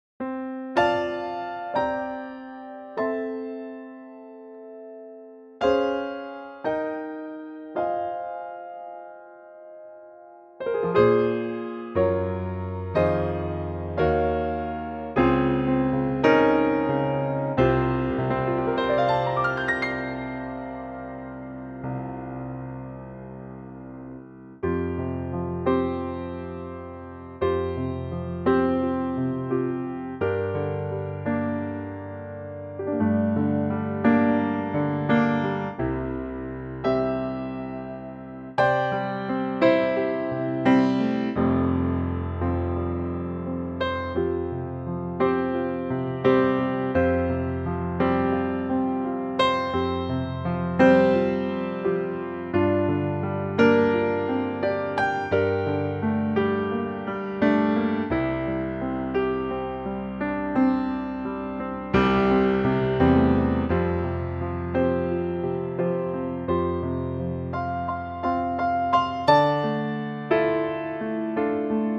key - F - vocal range - F to C
Very lovely piano only arrangement